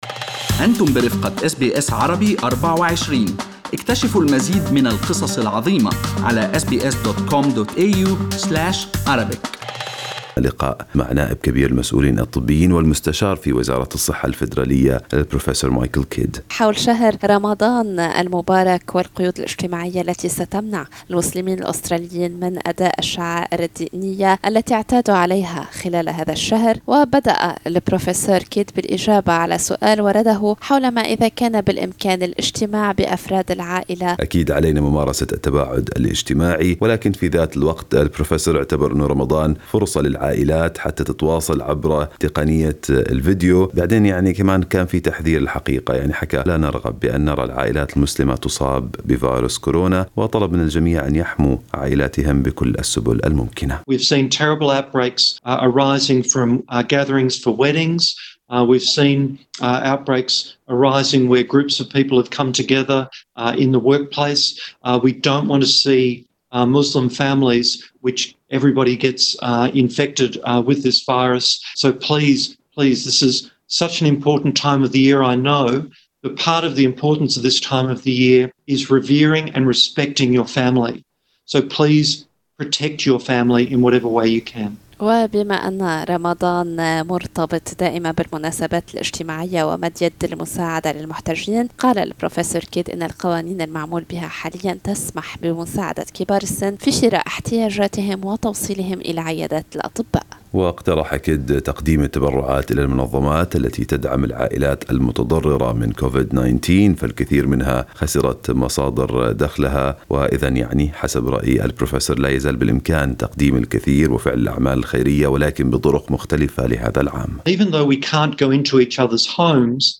أس بي أس عربي 24 تحدثت إلى نائب كبير المسؤولين الطبيين والمستشار في وزارة الصحة الفدرالية البروفسور مايكل كيد للحديث عن التدابير التي يتوجب على المسلمين اتباعها لتأدية فريضة الصيام والحفاظ على صحتهم وصحة المجتمع.